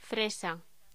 Locución: Fresa
voz